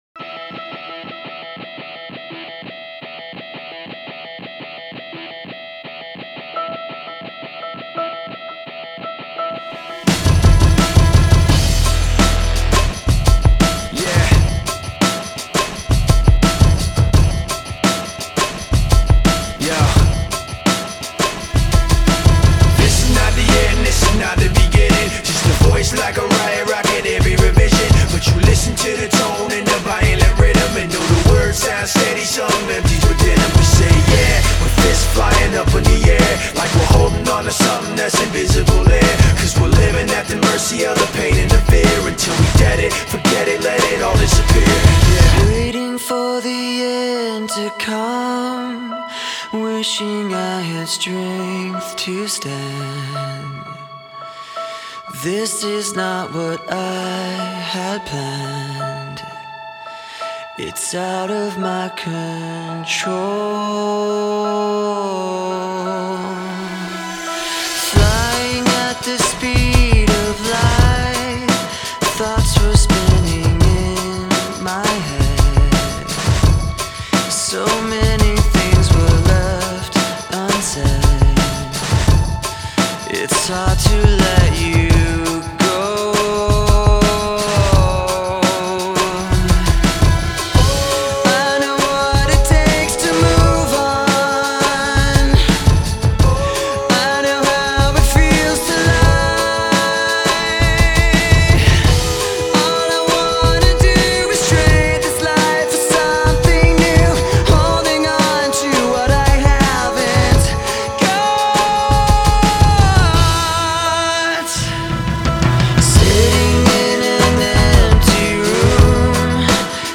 • Жанр: Rap, Rock